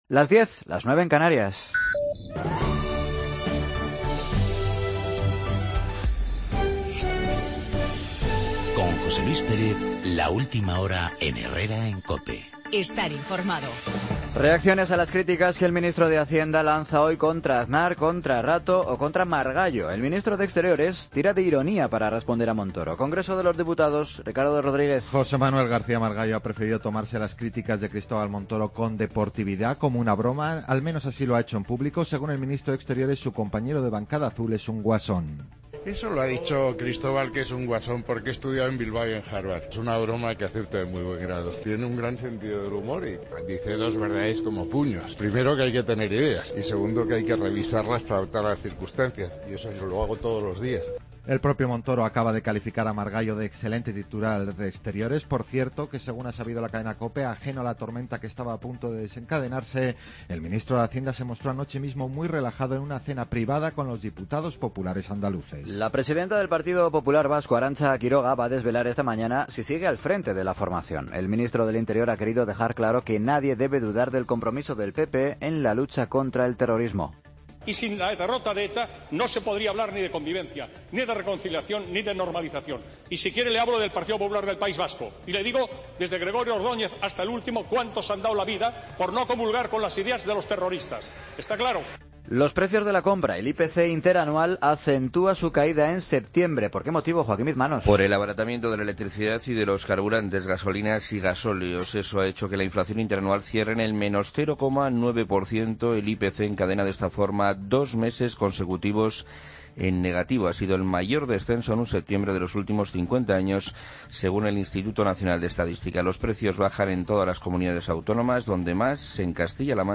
Noticias de las 10.00 horas, miércoles 14 de octubre de 2015